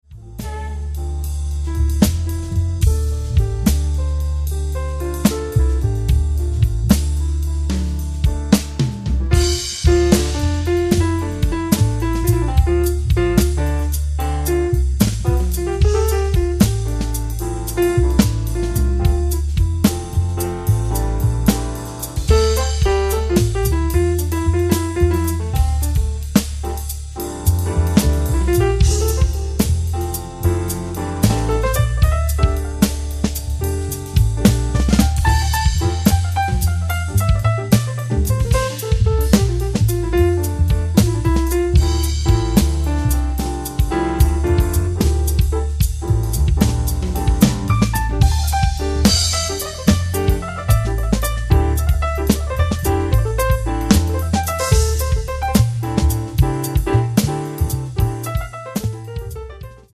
voice
chorus
guitar
keyboards & piano
bass
drums
percussions